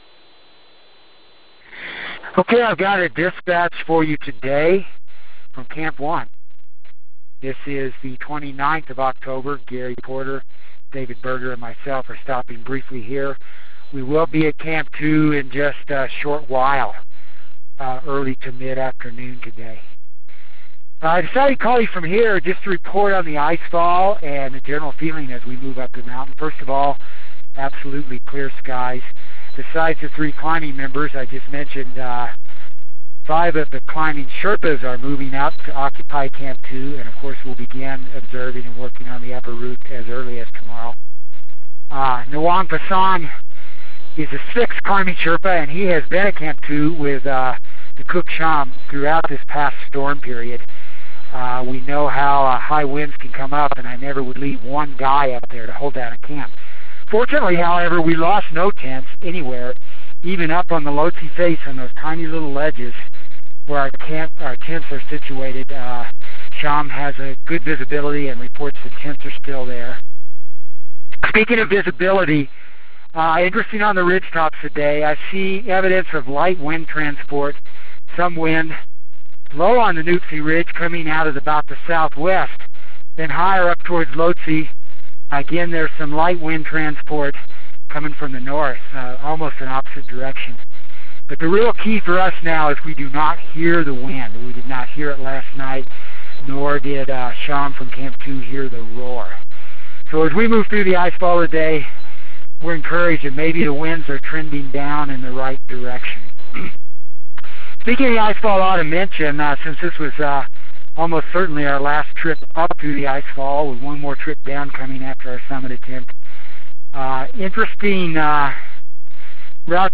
October 29 - Chasing the Sherpas to Camp 2